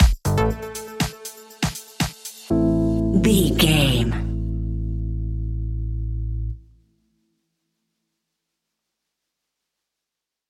Funky Bassline Electro Stinger.
Aeolian/Minor
groovy
uplifting
driving
energetic
drums
bass guitar
synthesiser
electric piano
funky house
deep house
nu disco
upbeat
synth bass